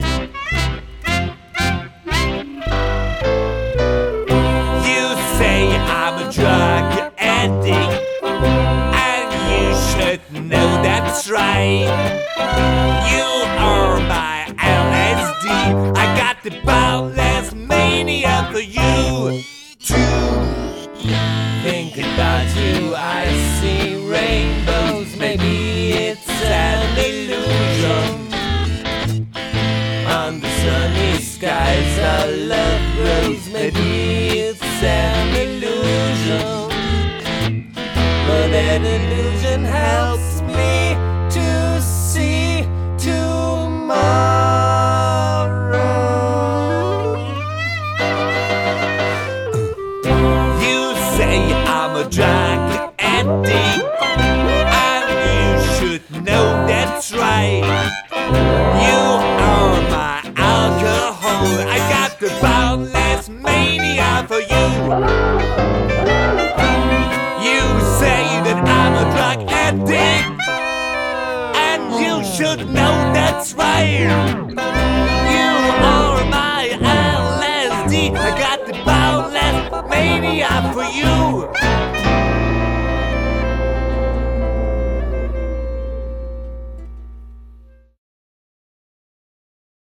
trumpet, kazzoo, electronics
clarinet
trombone, fake tuba